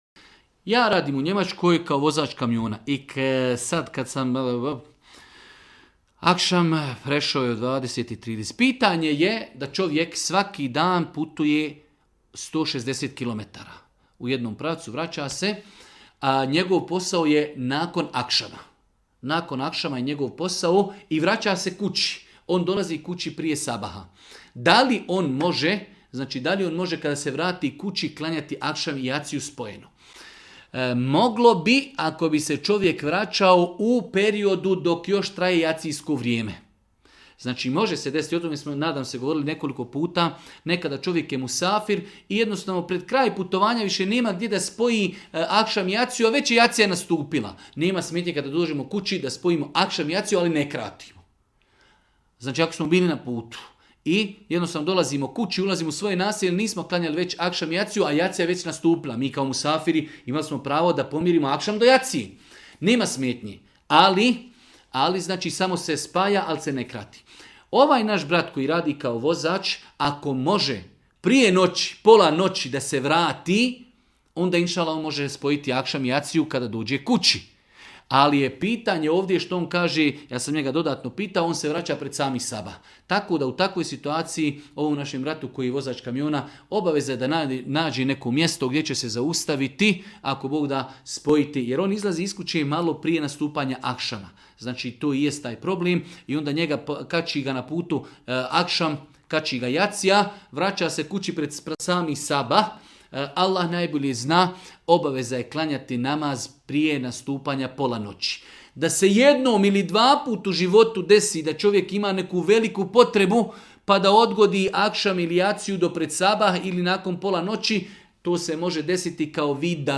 u video predavanju